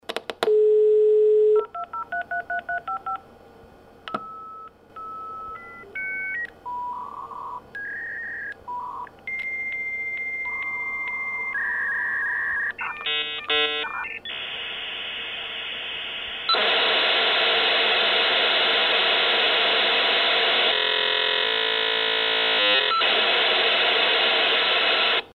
Знакомый шум установления соединения перенесет вас назад в 90-е. Все файлы доступны в высоком качестве для личного использования или творческих проектов.
Звук соединения модема